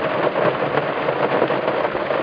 1 channel
RAIN_IN.mp3